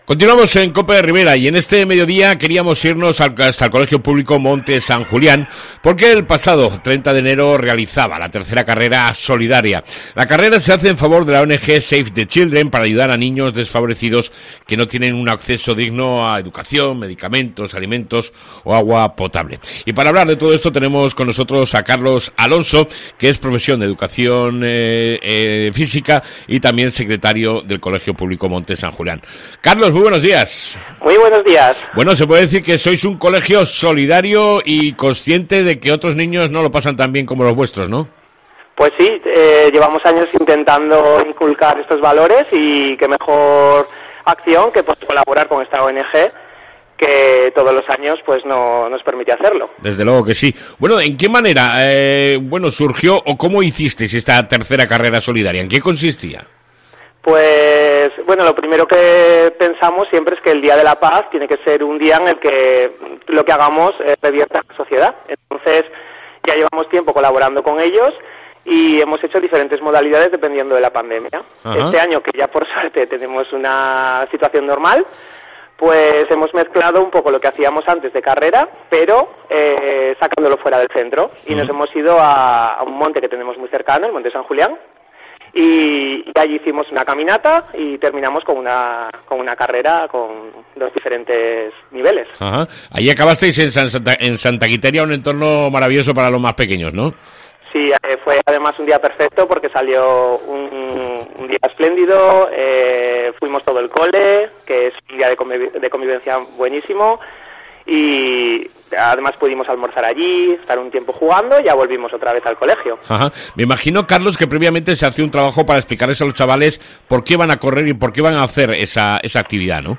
ENTREVISTA CON EL CP MONTE SAN JULIAN DE TUDELA